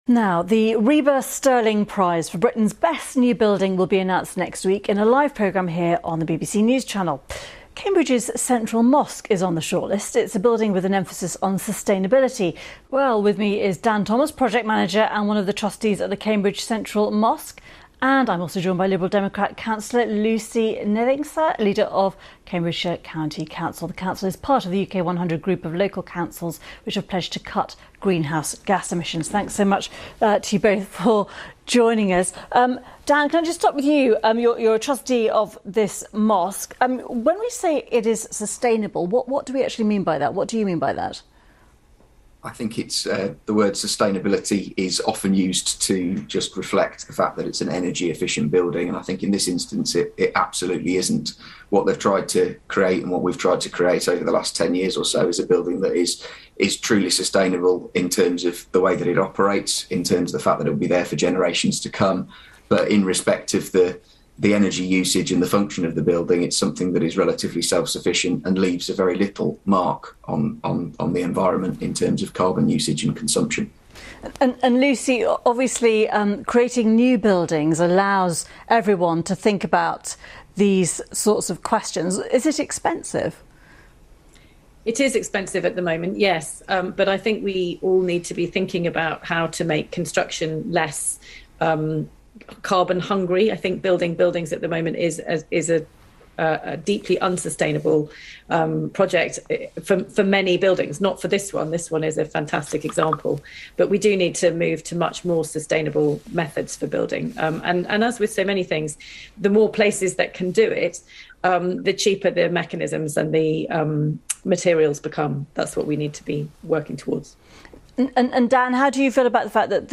BBC News： Interview on Sustainability.mp3